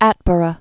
(ătbər-ə, ät-)